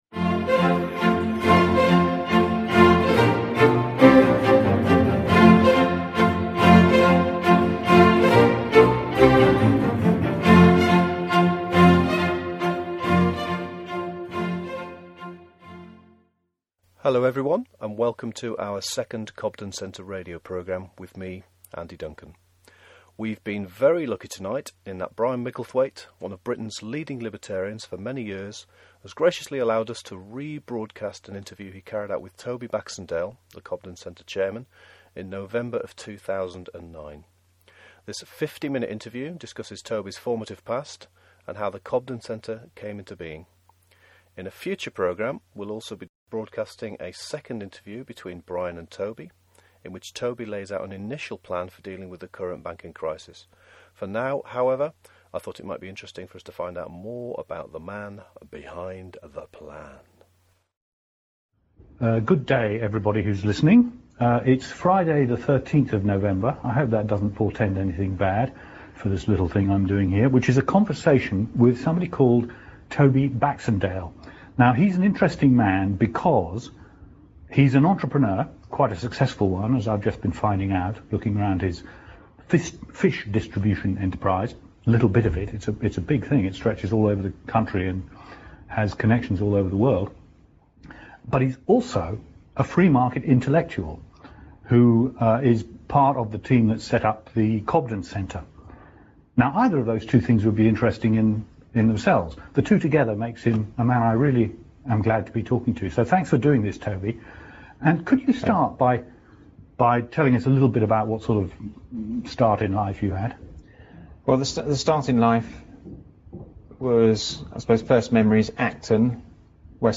This interview was originally recorded on Friday the 13th, in November of 2009.